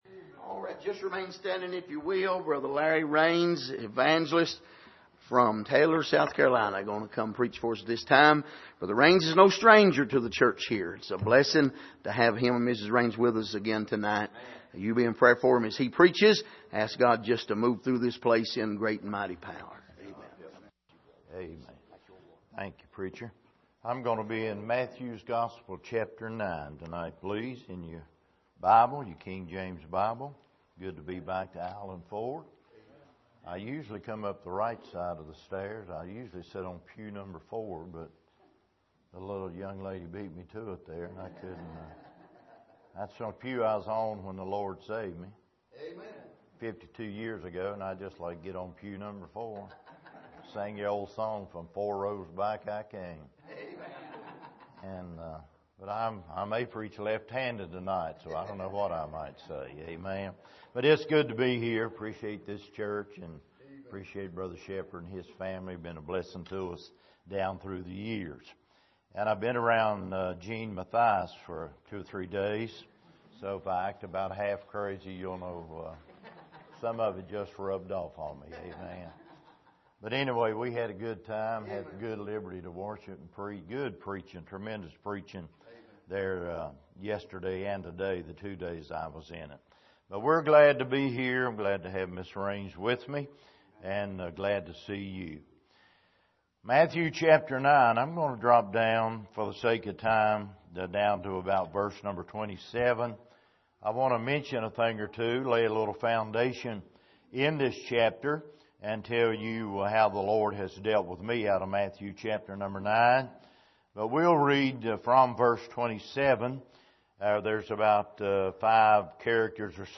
Here is an archive of messages preached at the Island Ford Baptist Church.
Service: Sunday Evening